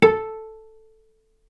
vc_pz-A4-ff.AIF